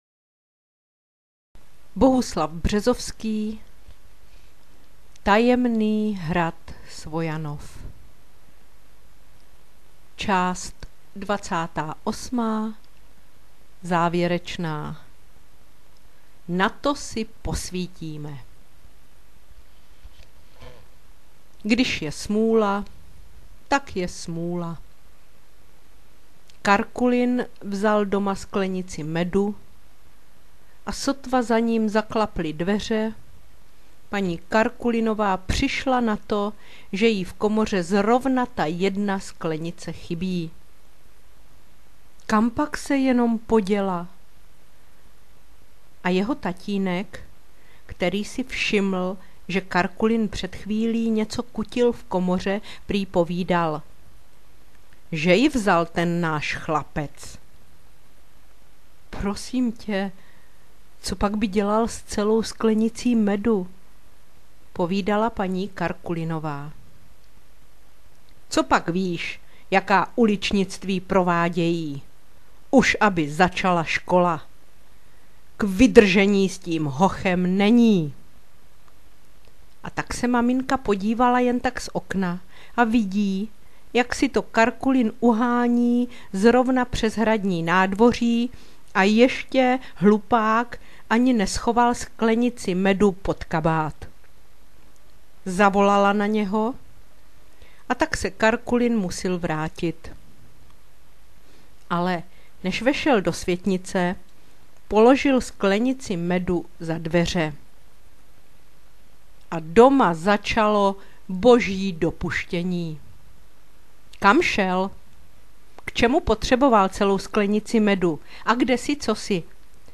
Na deštivé a chladné jarní dny i večery připravila knihovnice četbu z knihy Bohuslava Březovského Tajemný hrad Svojanov aneb Paměti Františka Povídálka jako takzvanou „knížku do ucha“.